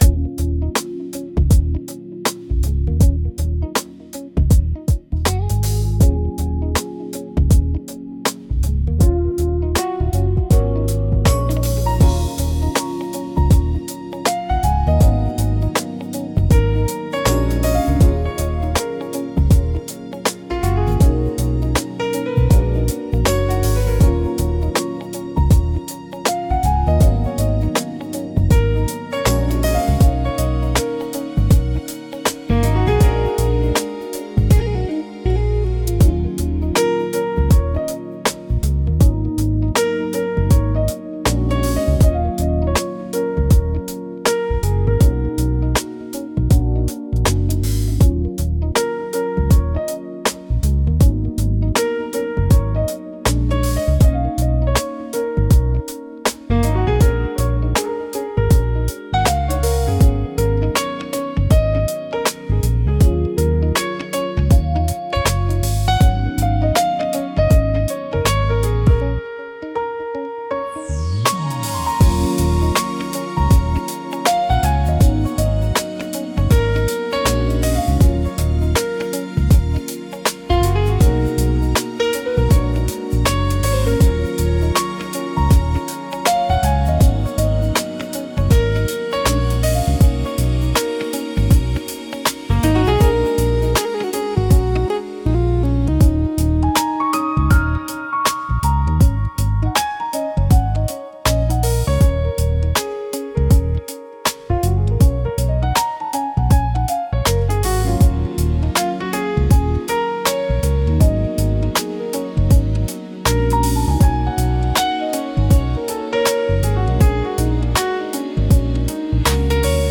チルアウトは、ゆったりとしたテンポと滑らかで広がりのあるサウンドが特徴のジャンルです。
リラックス効果の高いメロディと穏やかなリズムにより、心地よい安らぎの空間を作り出します。
穏やかで心地よい雰囲気作りに重宝されるジャンルです。